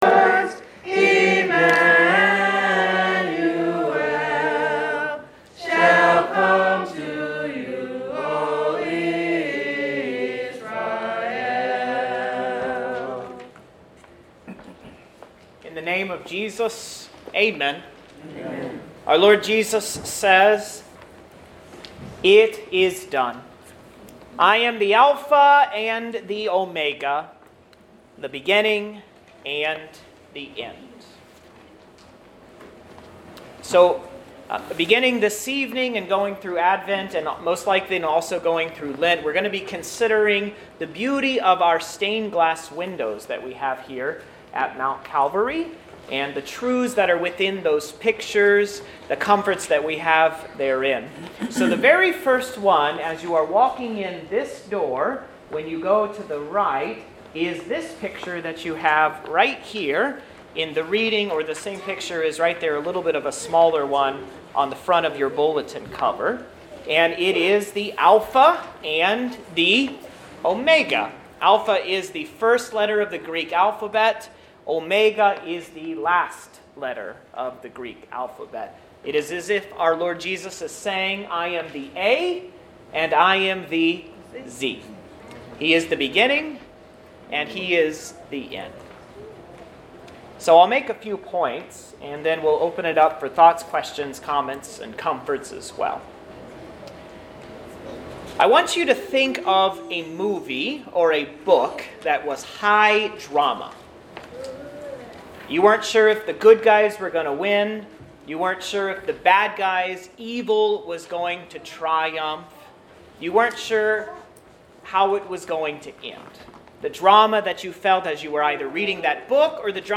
SERVICE OF LIGHT (Dec. 5, 2018)